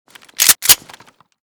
spas12_unjam.ogg